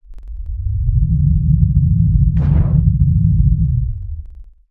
harvesterRetract.wav